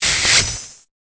Cri de Scalpion dans Pokémon Épée et Bouclier.